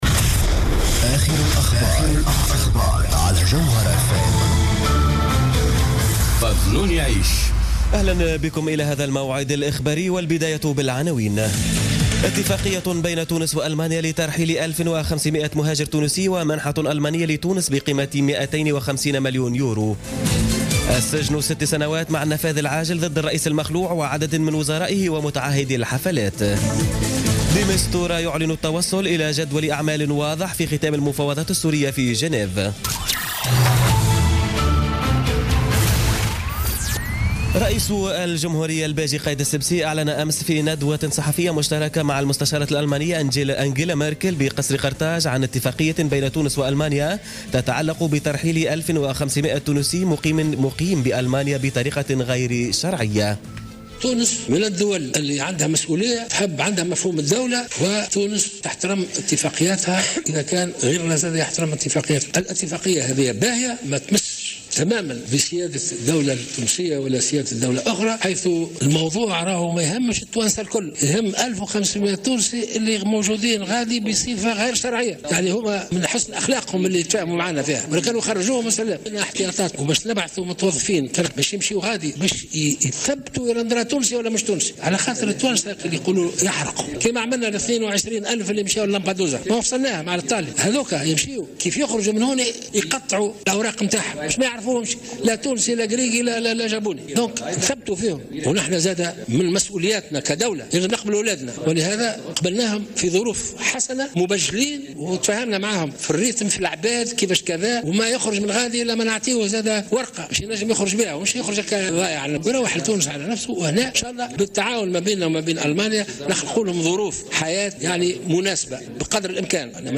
Journal Info 00h00 du samedi 4 Mars 2017